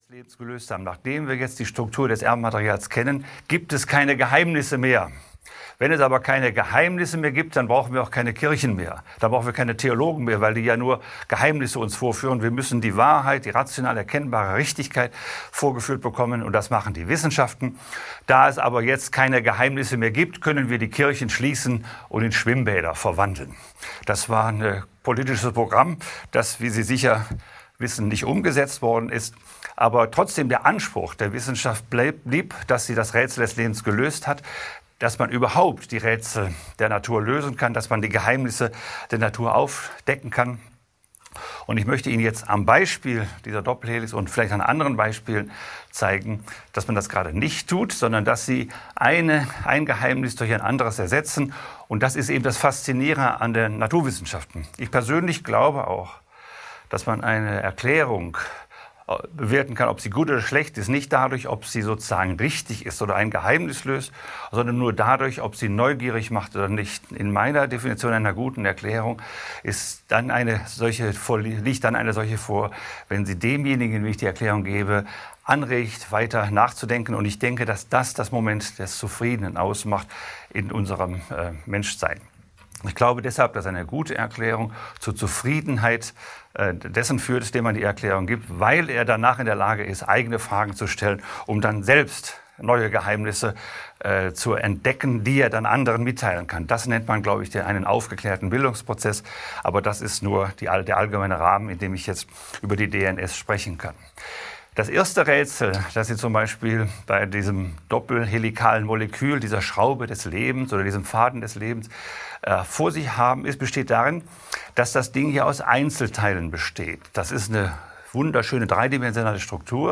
Das Rätsel des Lebens bleibt ein Geheimnis - Ernst Peter Fischer - Hörbuch